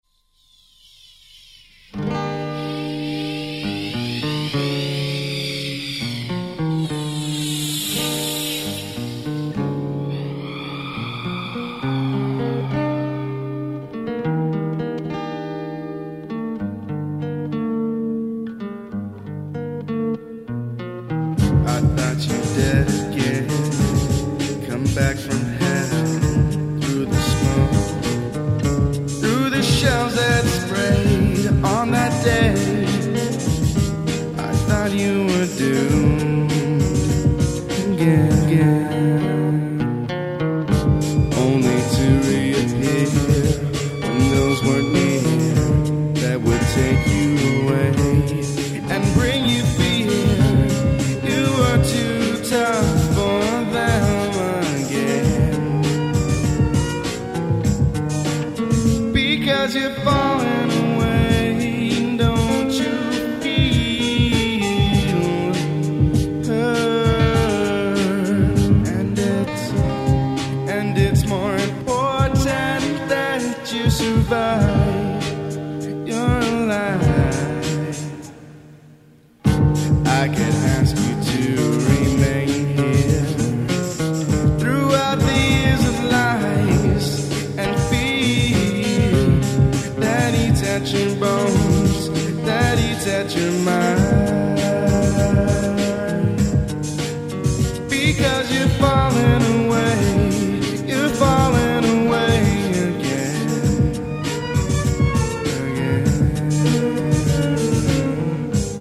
Alternative Vocal Song